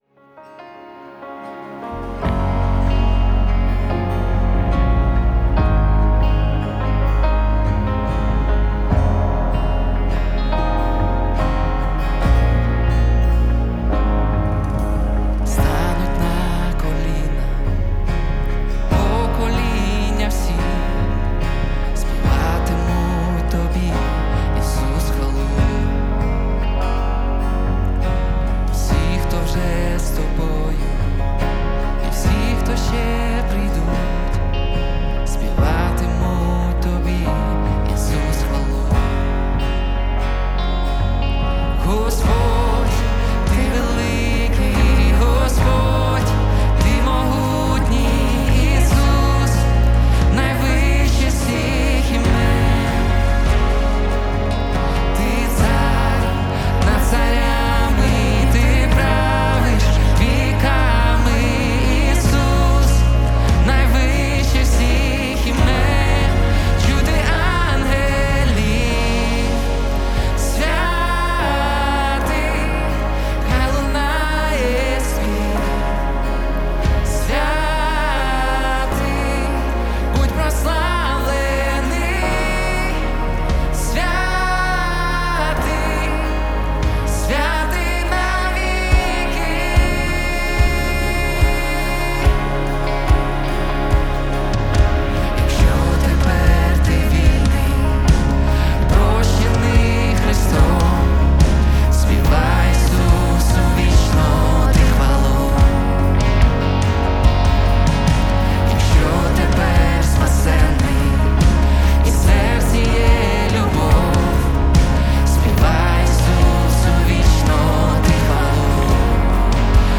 851 просмотр 7 прослушиваний 1 скачиваний BPM: 72